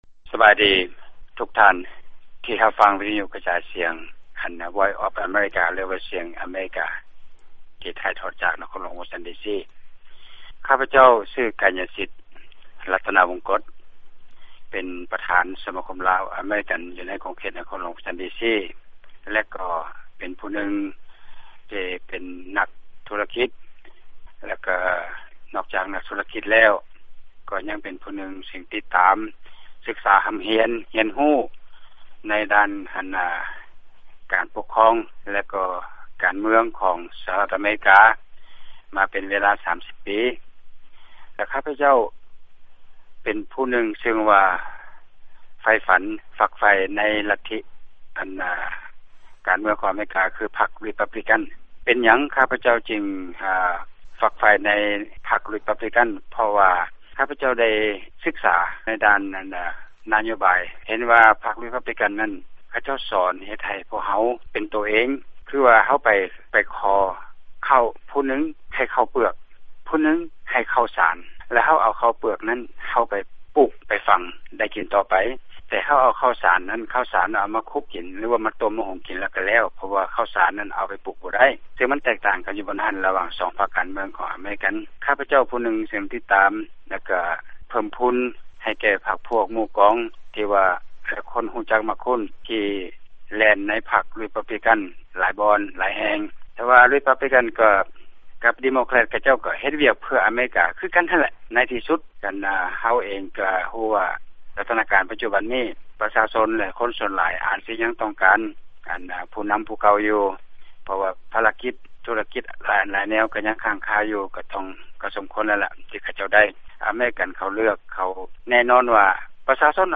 ຟັງຂ່າວ ການສໍາພາດ